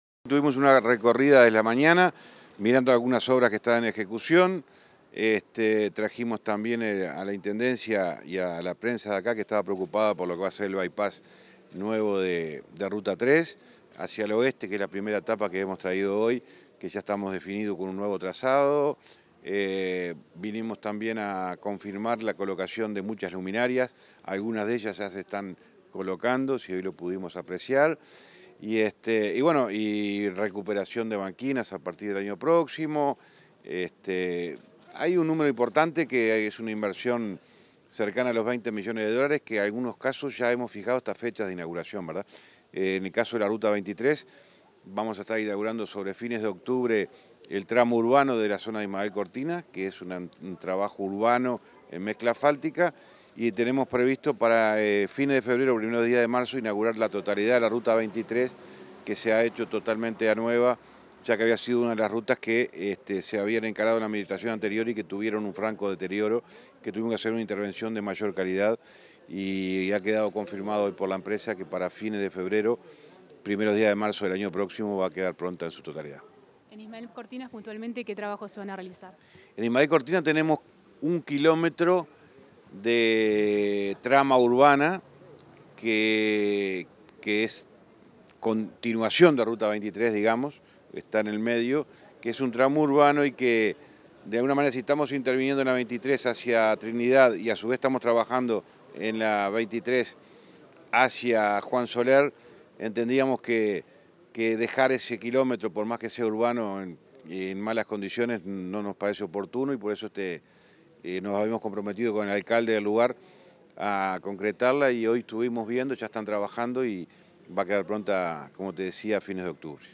Entrevista al ministro de Transporte y Obras Públicas, José Luis Falero